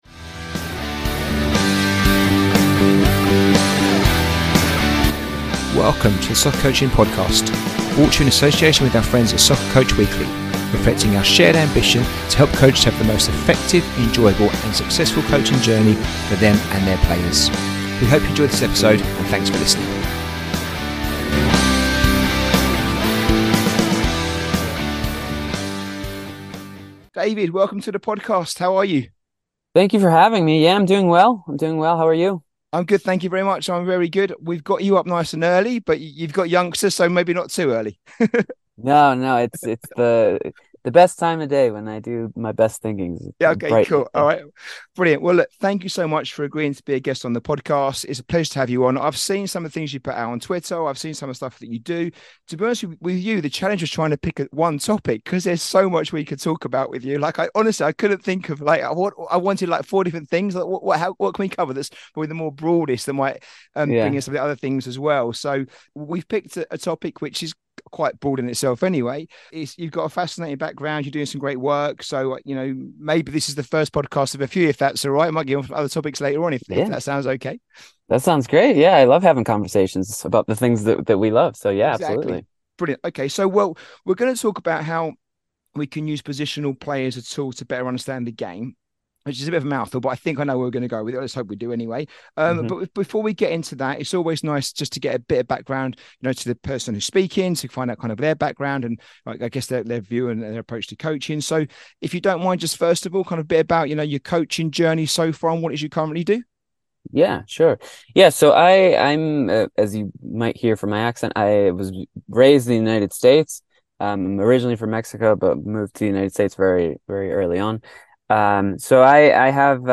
Episode 103 - Using Positional Play To Better Understand The Game, a conversation